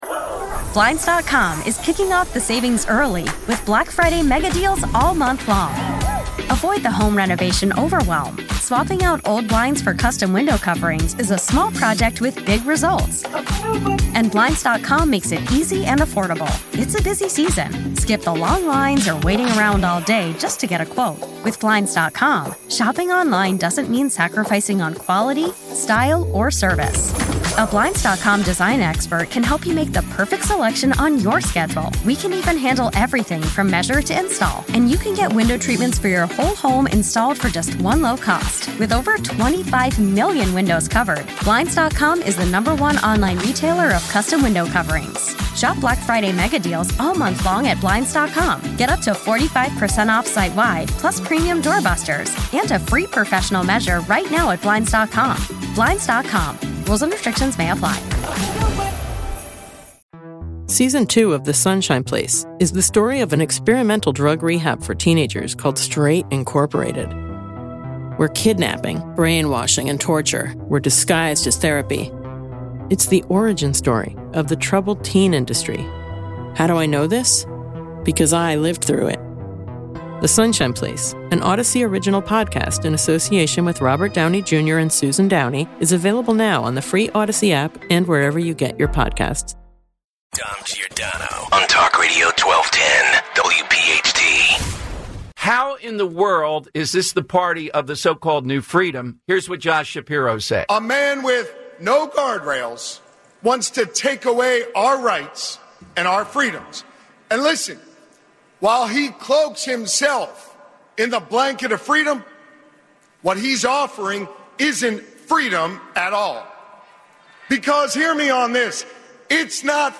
Then, we hear from Bill Clinton himself, who reveals is favorite parts of the convention.